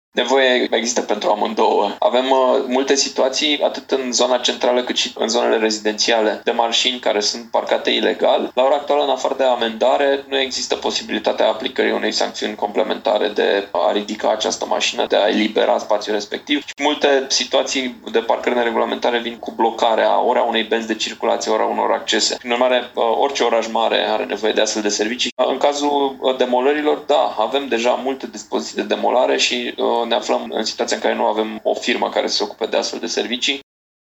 Primarul municipiului Brașov, Allen Coliban: